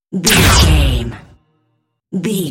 Dramatic hit laser
Sound Effects
Atonal
heavy
intense
dark
aggressive